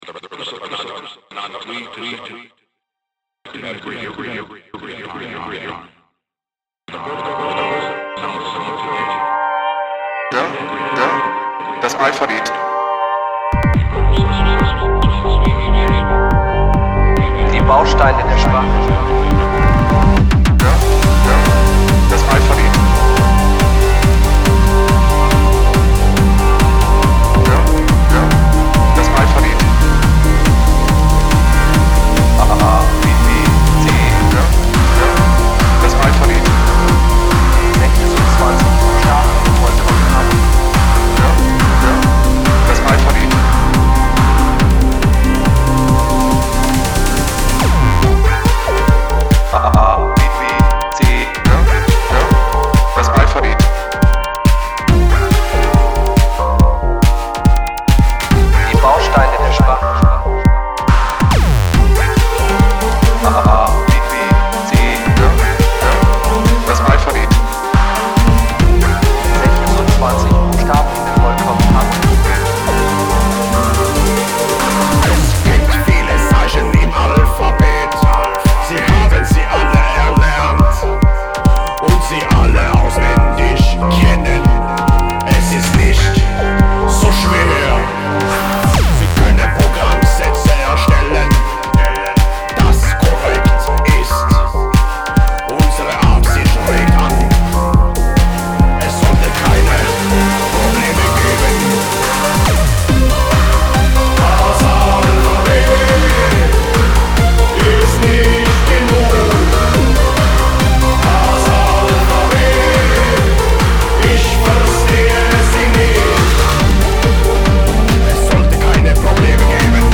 Áîòò, ëîâè EBM.